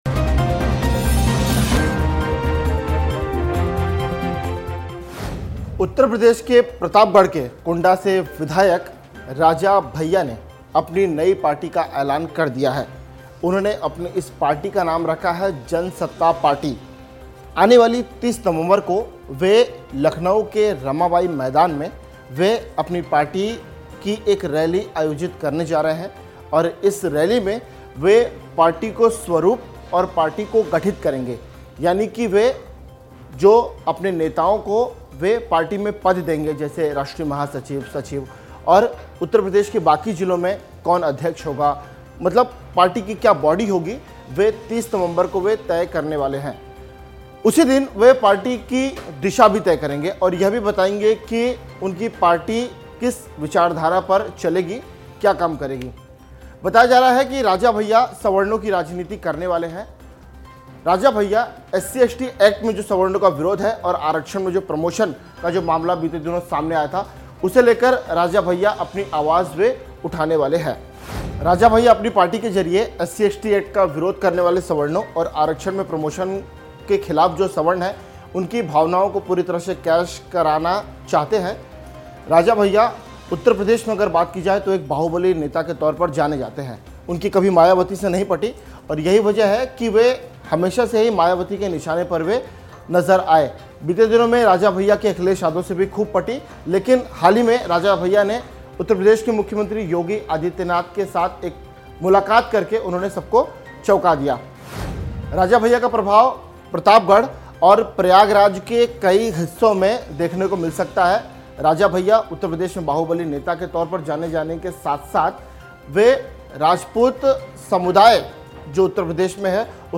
न्यूज़ रिपोर्ट - News Report Hindi / राजा भैया ने जनसत्ता पार्टी क्यों बनाई, असली वजह !